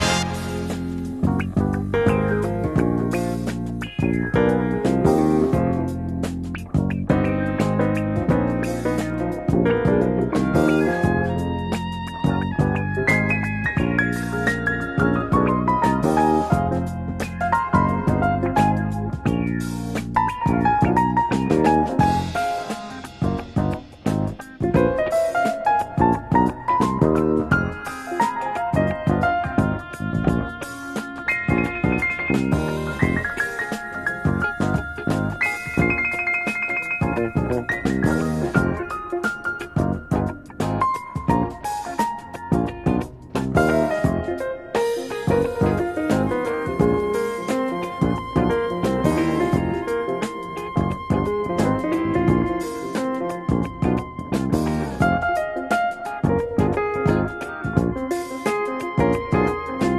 jazz-funk
kicks off with a tight, syncopated drum pattern
silky saxophone